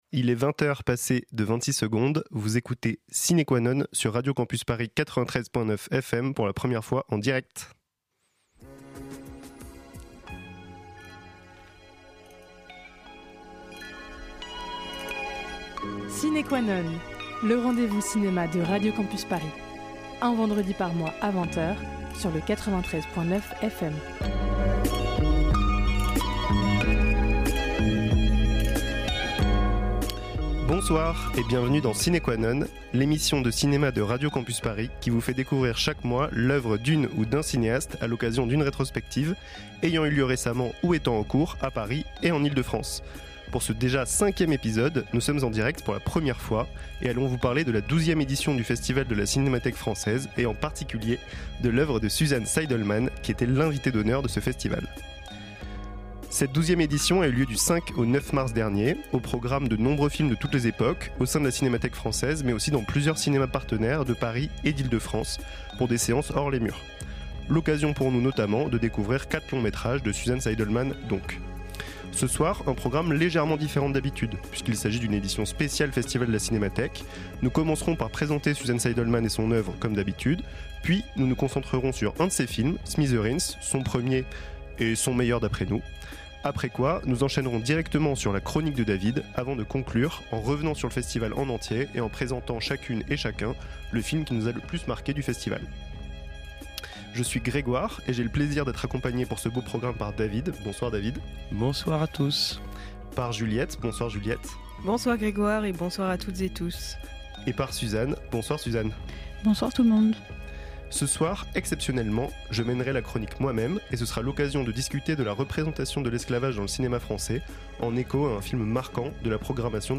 Votre rendez-vous mensuel : une émission Radio Campus Paris où l'on parle de cinéma et plus particulièrement de rétrospectives de réalisateur·ices fortiches.